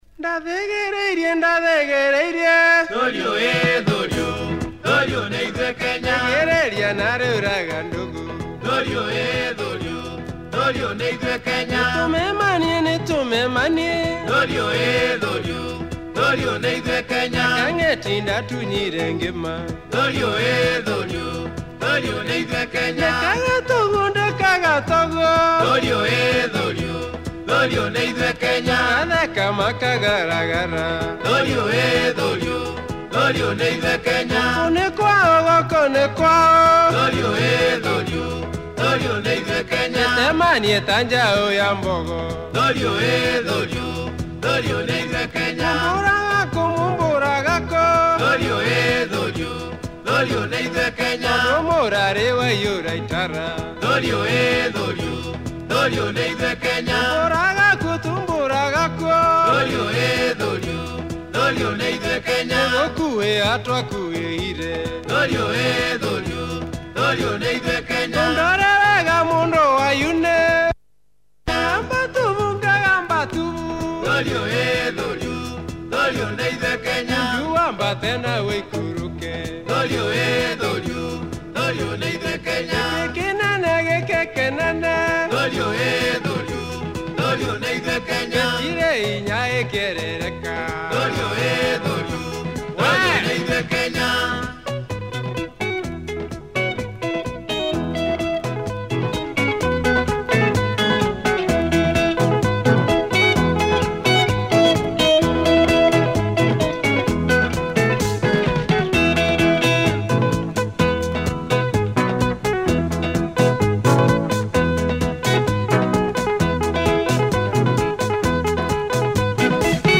Good groove